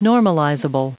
Prononciation du mot normalizable en anglais (fichier audio)
Prononciation du mot : normalizable